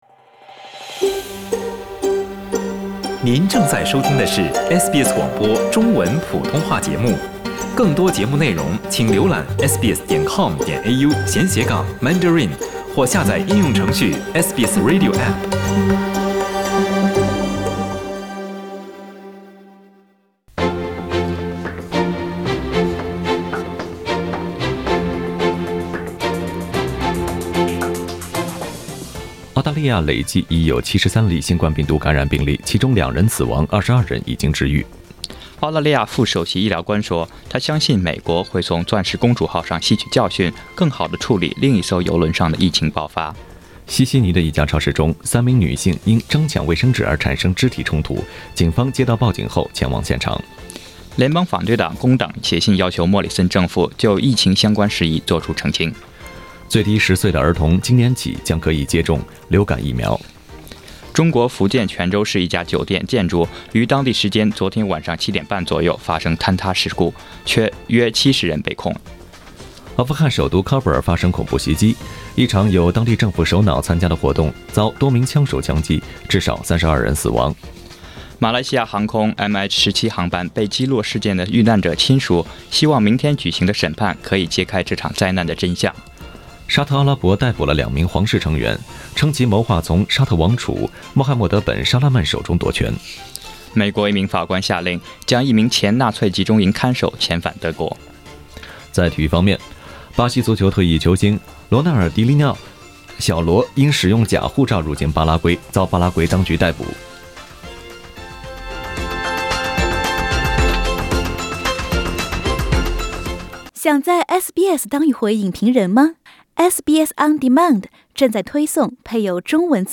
SBS早新闻（3月8日）